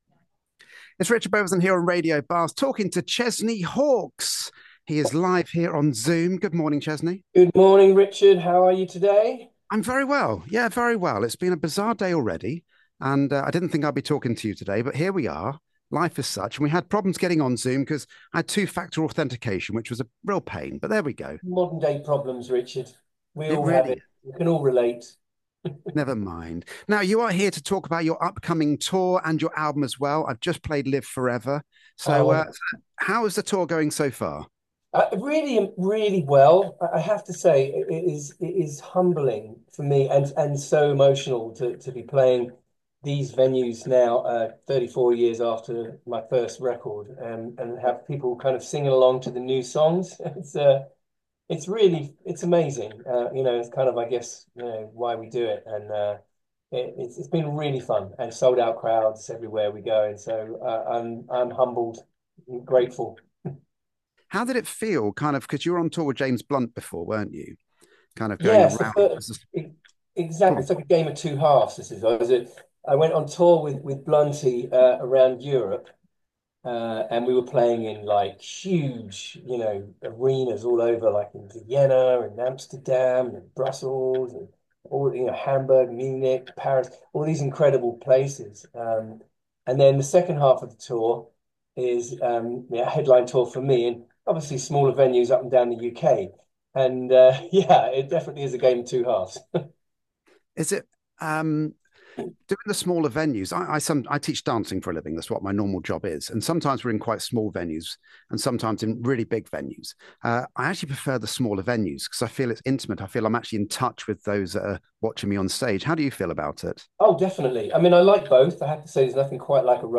The one and only Chesney Hawkes live on Radio Bath - Radio Bath: A Voice For Bath